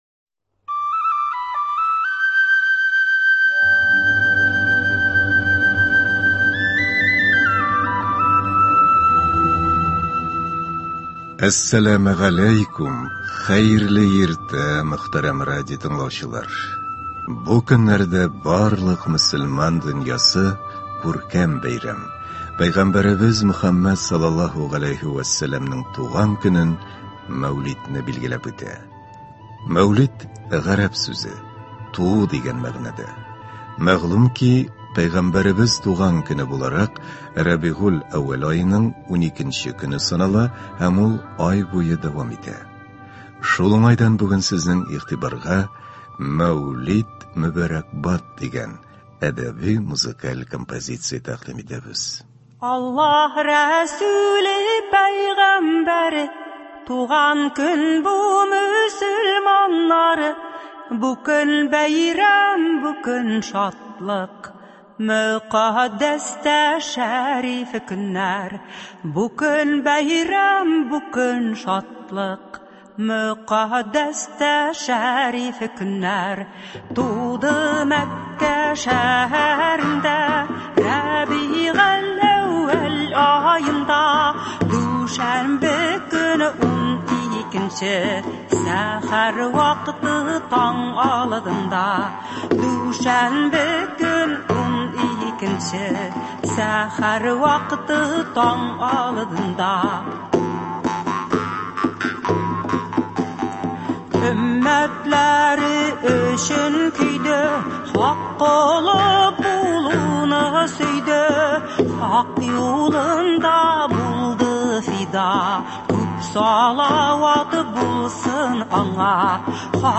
Шул уңайдан бүген сезнең игътибарыгызга “Мәүлид мөбарәкбад!” дигән әдәби-музыкаль композиция тәкъдим итәбез.